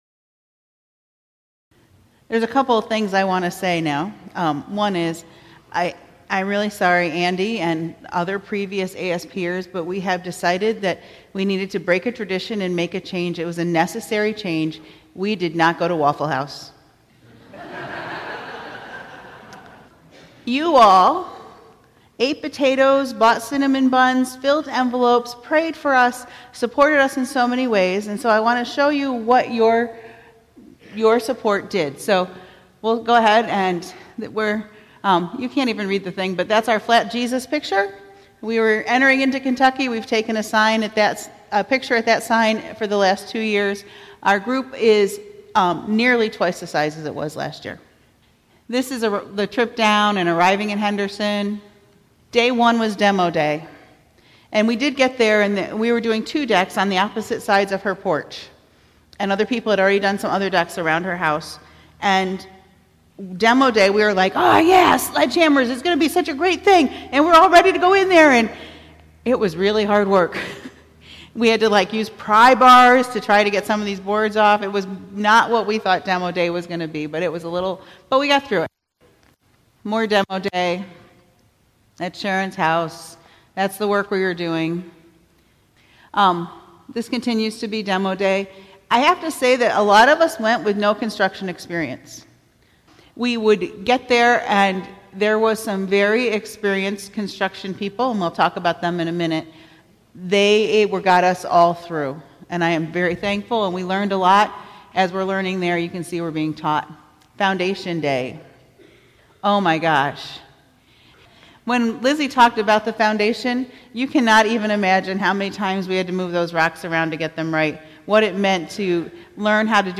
June2418-Sermon.mp3